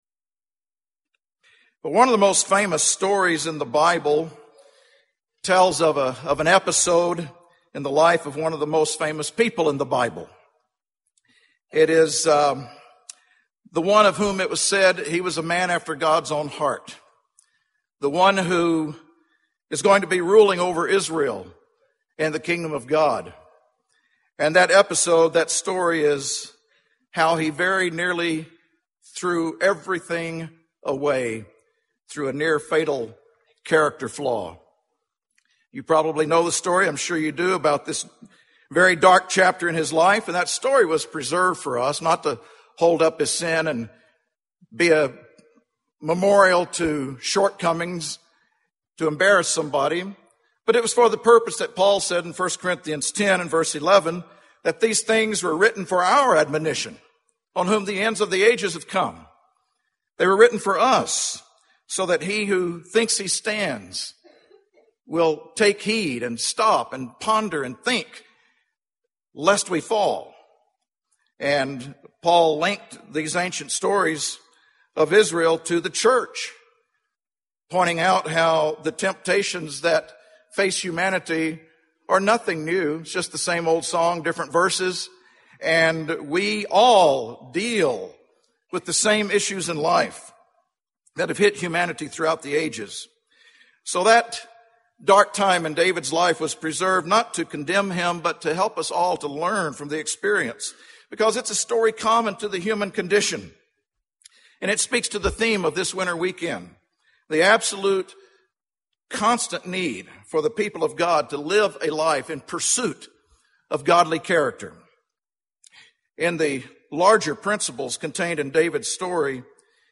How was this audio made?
Why must Christians be on a lifelong quest to build godly character? This year's Winter Family Weekend theme centered on godly character, and this sermon examines how the greatest spiritual battles of our lives are often fought internally, and they are won or lost in the arena of our character.